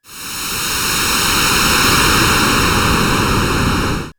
GasReleasing05.wav